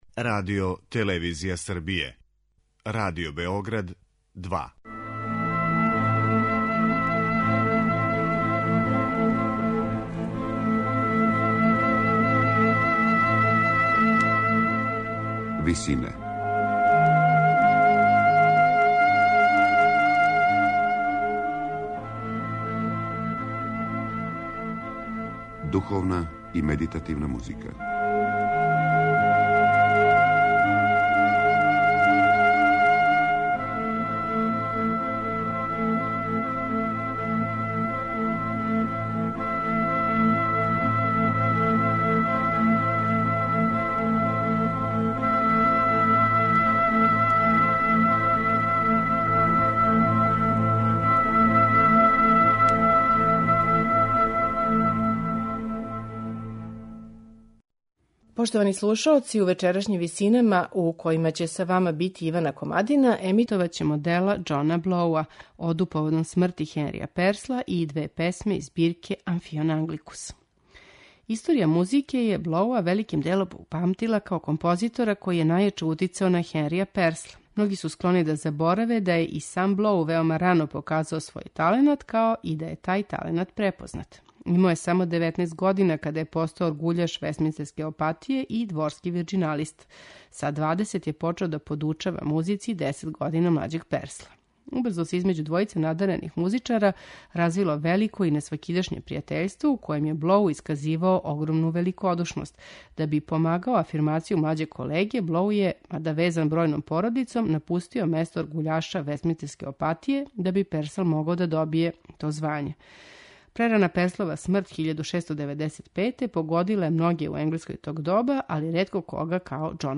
контратенори
флауте
виолончело
чембало